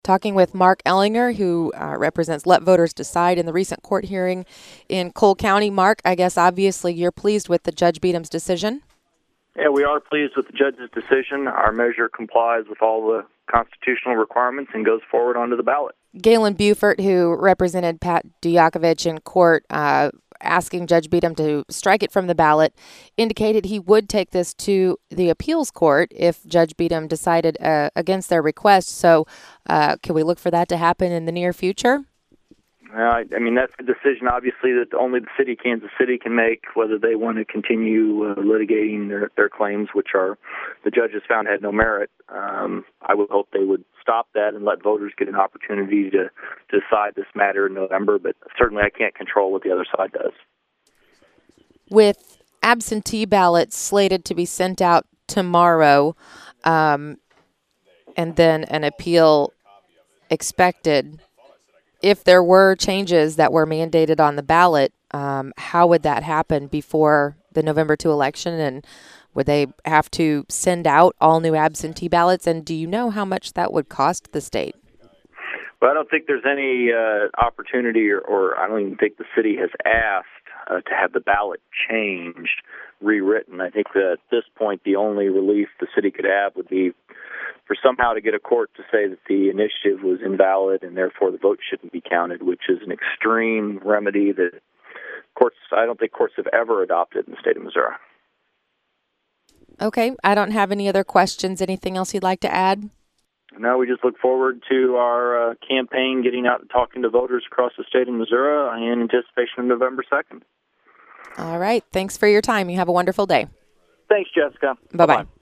outside of the Cole County Courthouse